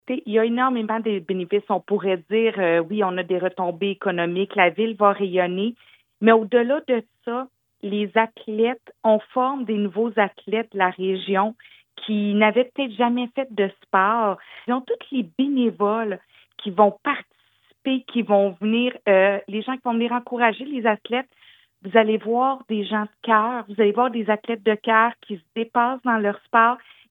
En entrevue sur nos ondes lundi matin, Mme Bourdon a dit avoir très hâte de recevoir les athlètes, qui vont arriver la semaine prochaine.